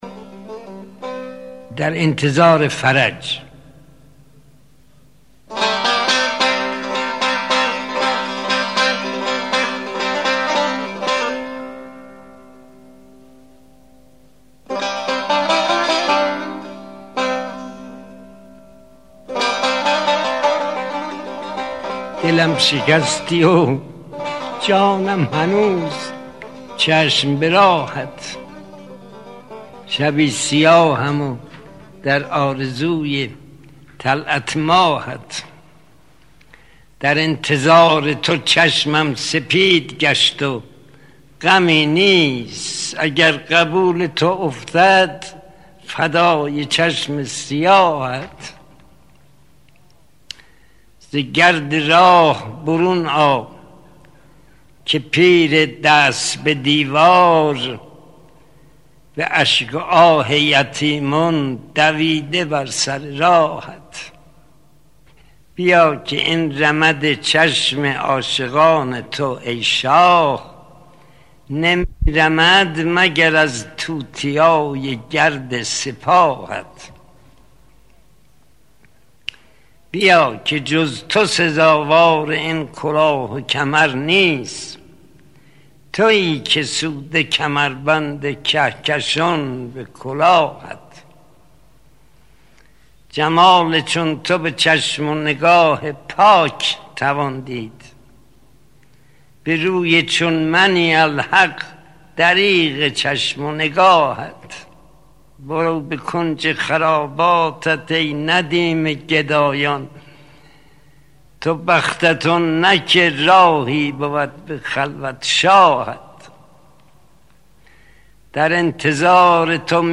لینک دانلود صدای استاد شهریار